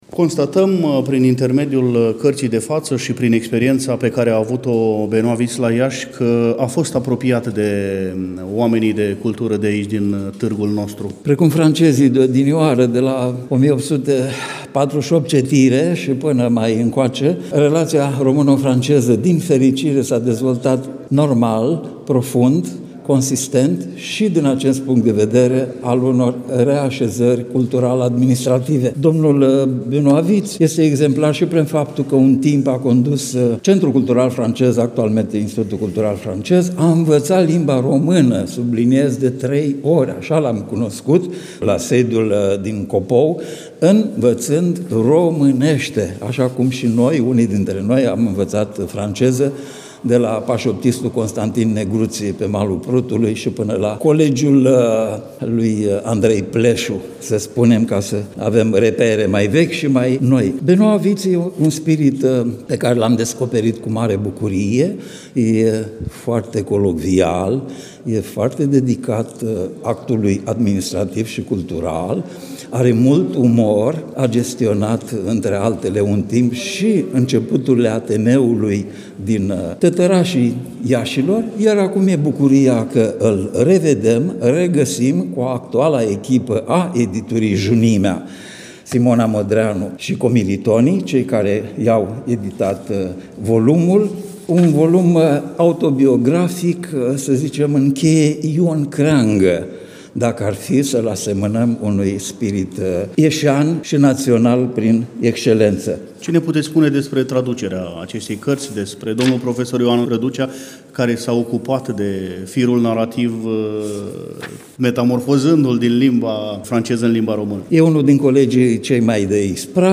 eveniment desfășurat, la Iași, nu demult, în Institutul Cultural Francez din incinta Palatului Braunstein.